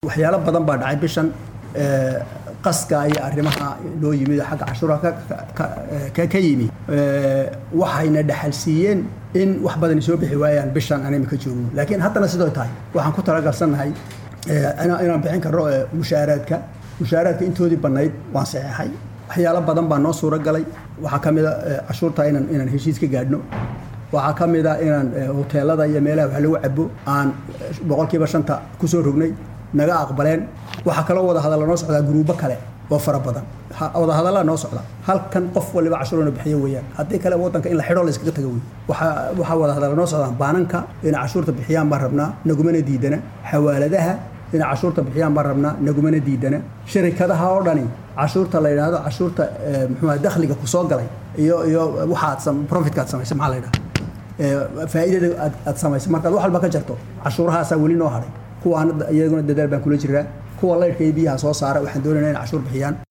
Dhagayso wasiirka
Wasiir-beyla.mp3